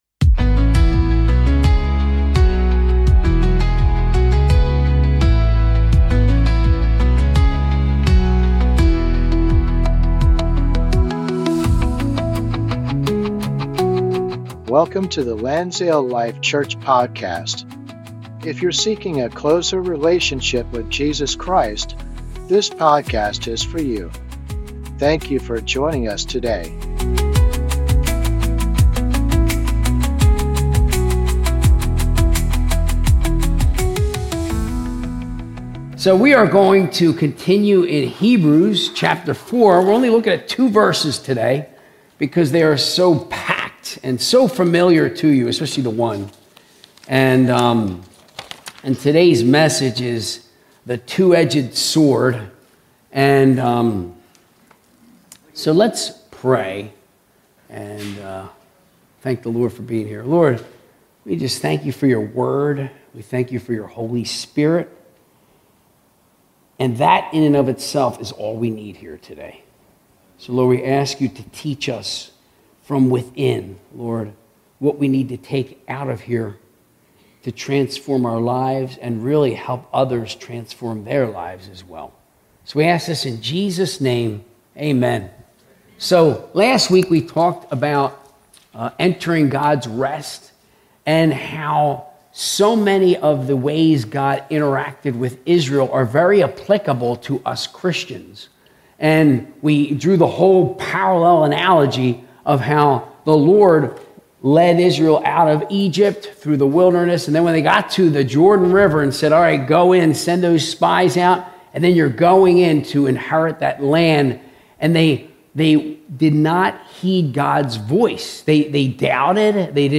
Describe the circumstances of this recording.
Sunday Service - 2025-07-27